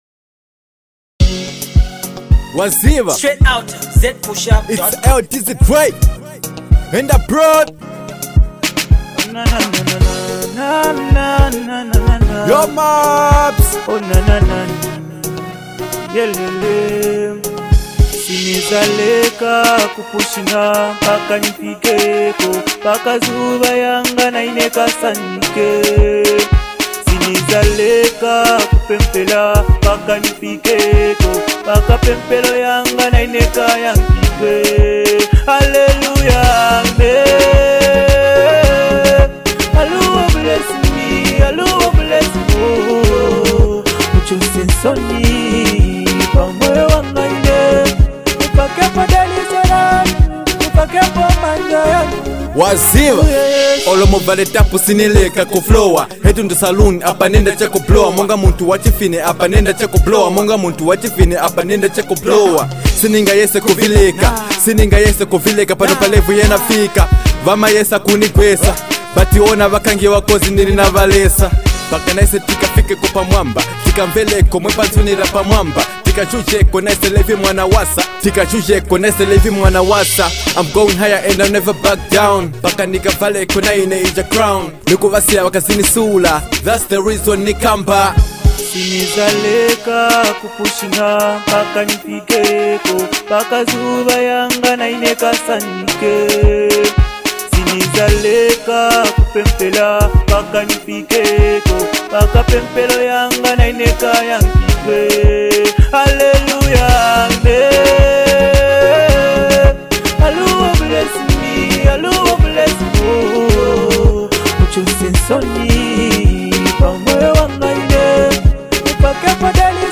motivational track